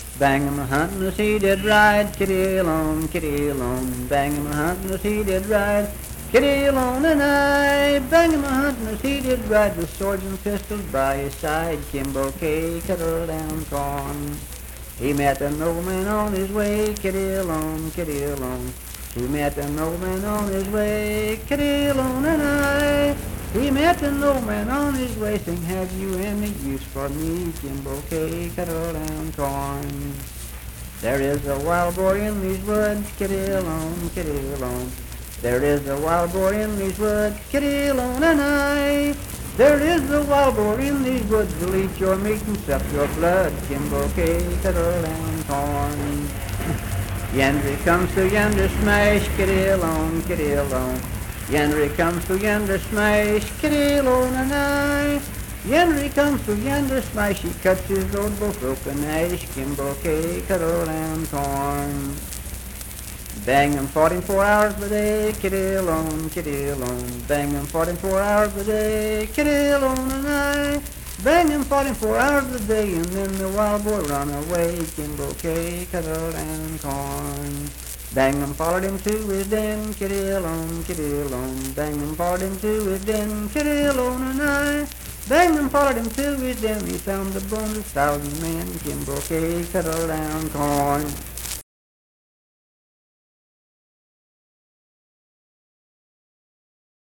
Unaccompanied vocal music performance
Verse-refrain 6(4w/R).
Voice (sung)